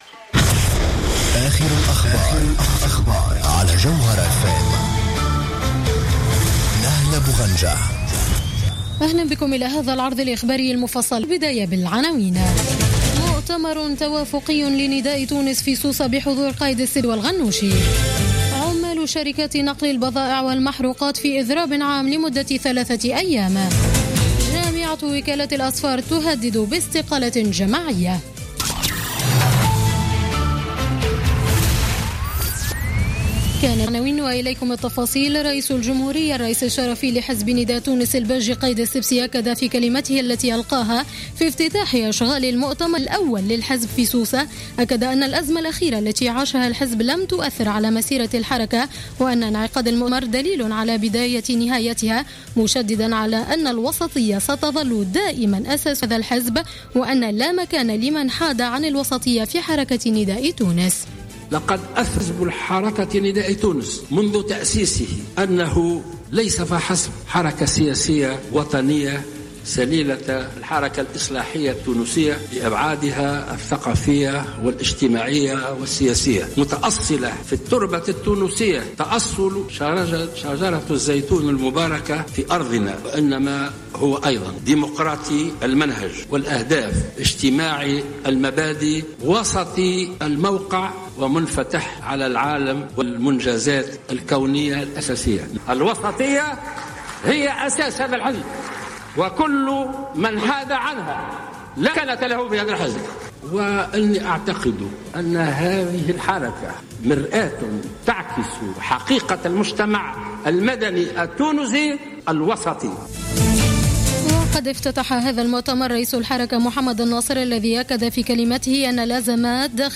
Journal Info 19h00 du samedi 09 Janvier 2016